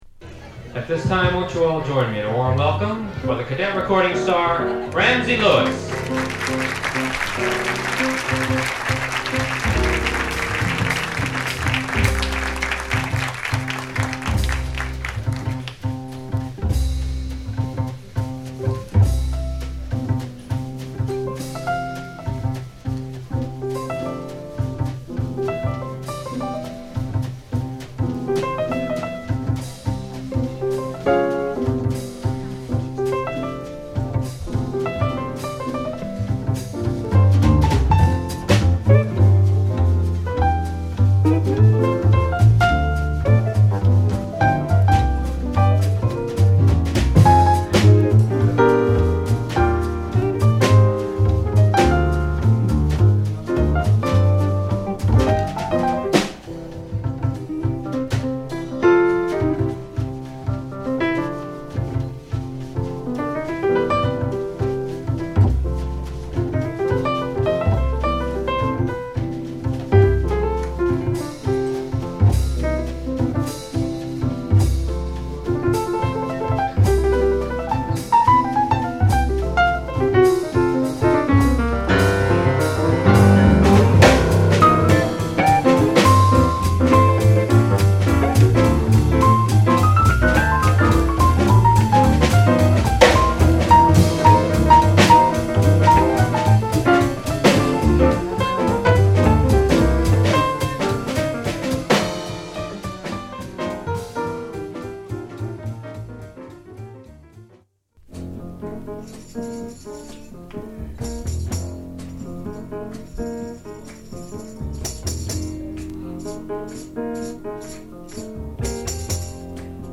息のあったスリリングなライブを披露！リラックスした雰囲気で始まるSideBも良いです。